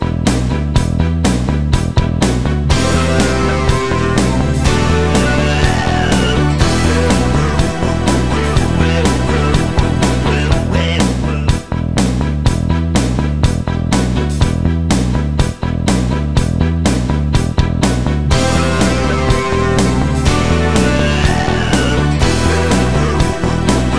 no Backing Vocals Rock 4:03 Buy £1.50